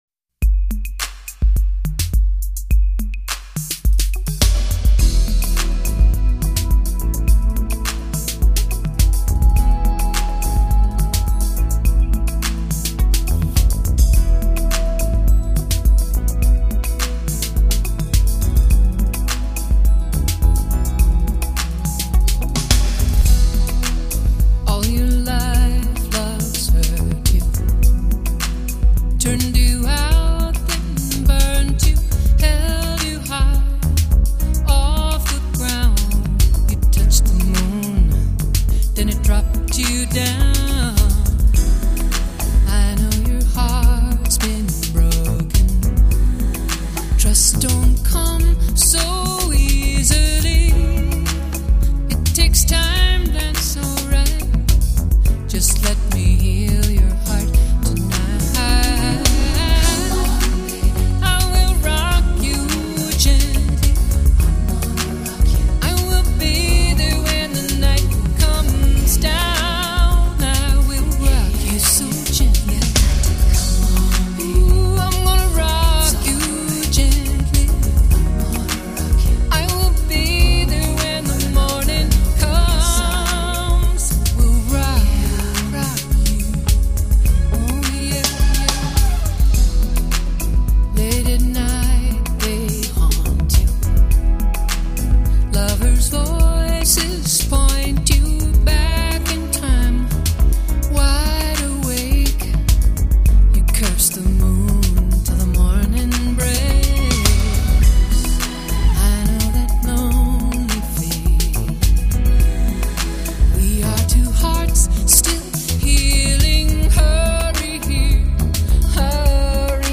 音乐类型：FOLK/POP